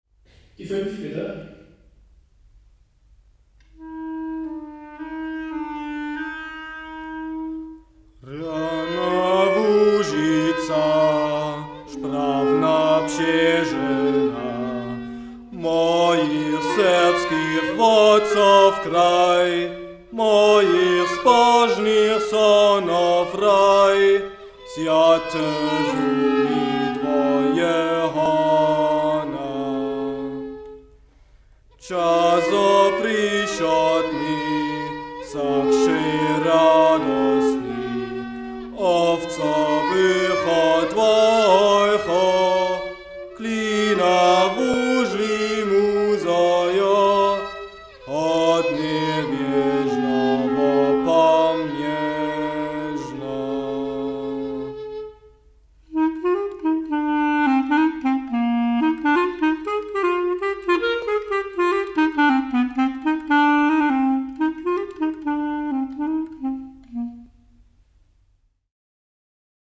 no, it wasn’t a punk rock band but a punk project of five totally different young men from G.D.R. / East Germany once decided to bring the world something between contemporary music, industrial, punk, noise and jazz.
gefunden im Archiv: Rjana Luzica - die Sorbische Hymne (Handrij Zejler + Awgust Kocor) - in einer Aufnahme vom Herbst 1990